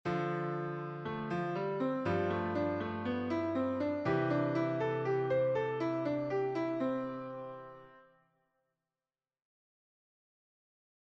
Improvisation Piano Jazz
Utilisation des Pentatoniques sur un ii V I Majeur